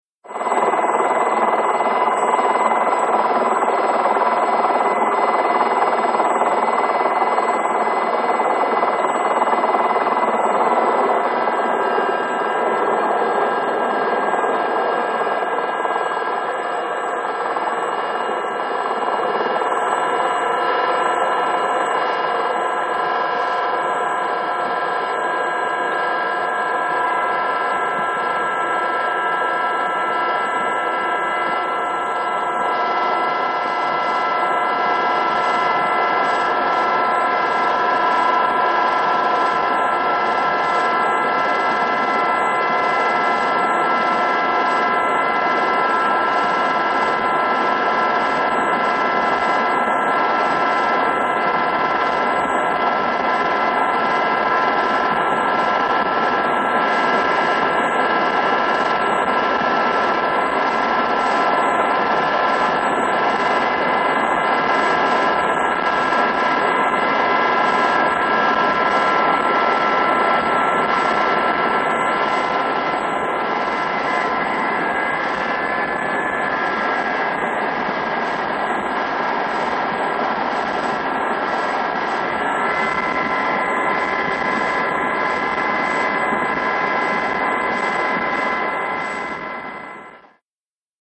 HELICOPTER AEROSPATIALE SA-341 GAZELLE: EXT: Hovering. Jet engine whine.